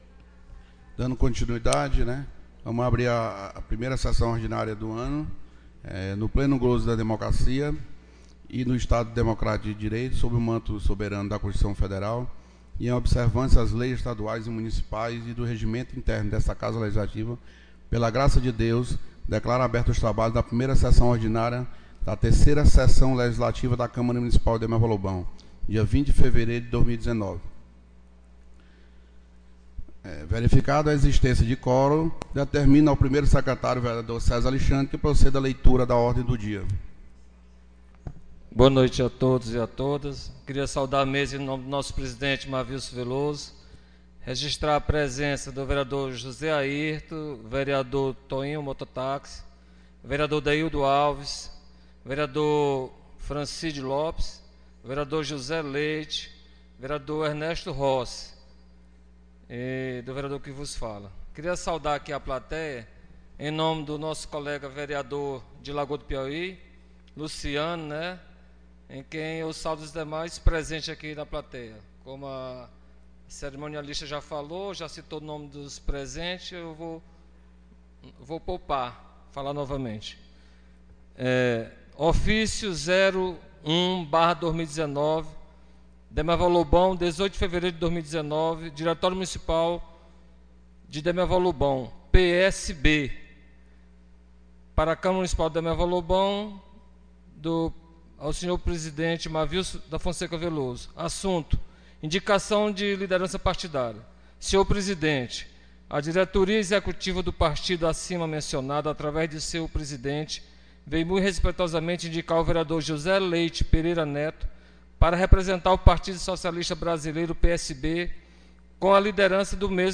1ª Sessão Ordinária 20 de Fevereiro